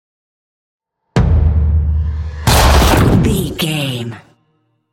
Double hit with whoosh shot explosion
Sound Effects
heavy
intense
dark
aggressive
hits